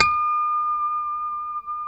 E6 PICKHRM2C.wav